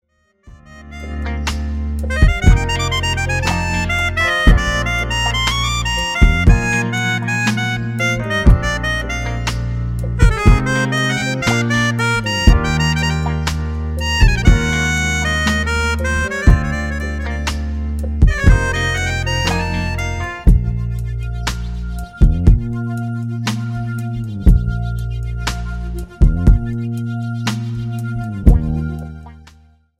JAZZ  (02.54)